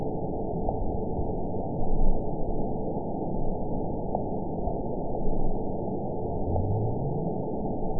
event 921665 date 12/15/24 time 09:22:58 GMT (11 months, 3 weeks ago) score 9.46 location TSS-AB03 detected by nrw target species NRW annotations +NRW Spectrogram: Frequency (kHz) vs. Time (s) audio not available .wav